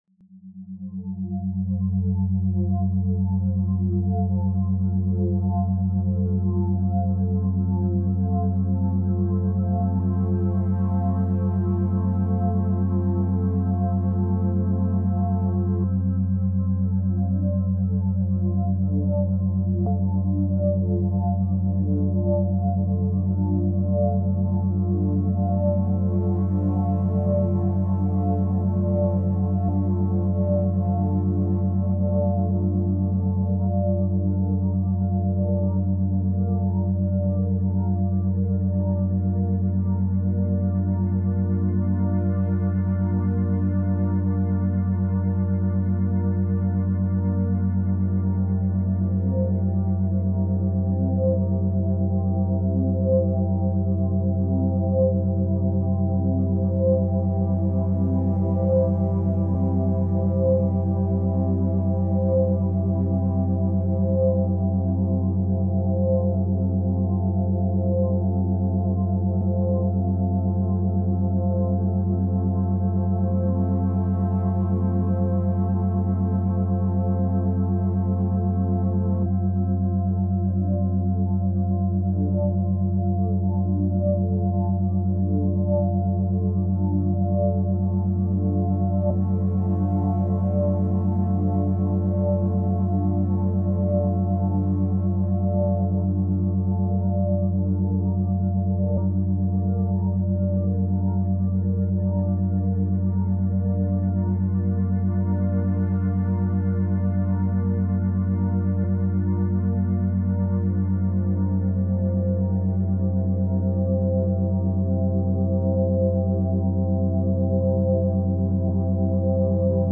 • Основная частота: Стабильные бинауральные биения 40 Гц